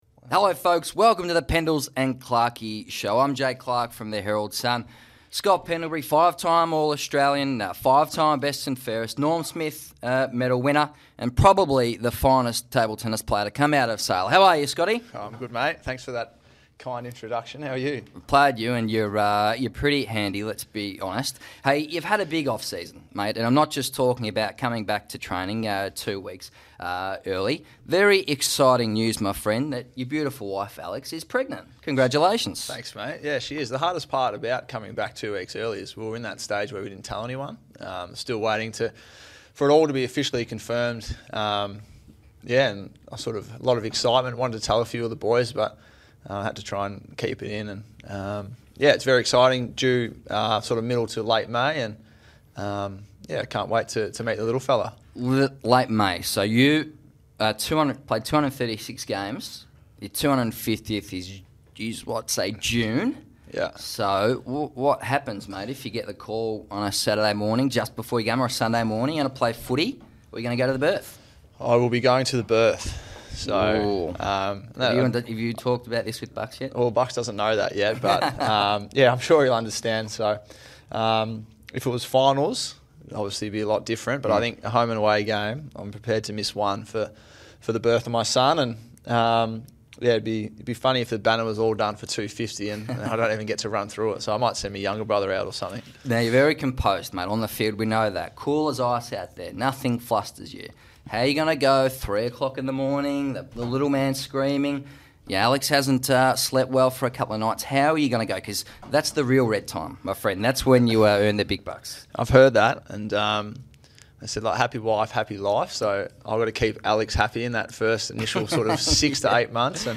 Listen to the pilot episode of a brand new all-sports podcast featuring Collingwood captain Scott Pendlebury